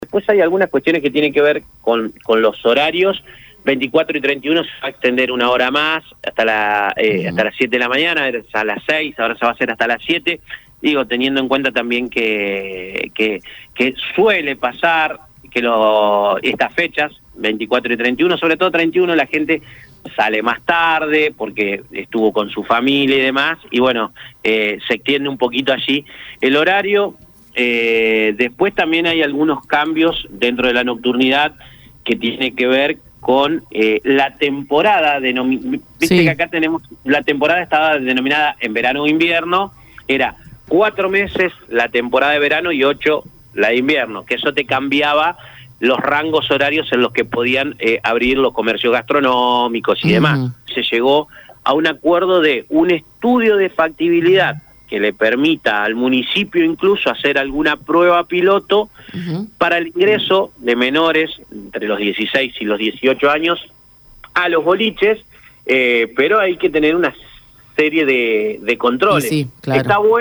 El concejal santafesino Ignacio «Nacho» Laurenti, representante de Vida y Familia, participó en una entrevista en Radio EME donde abordó varios temas de interés para la ciudad en el contexto de la reciente aprobación del Presupuesto 2025.
CONCEJAL-IGNACIO-LAURENTI-LLA-SOBRE-CAMIBIOS-EN-NOCTURNIDAD.mp3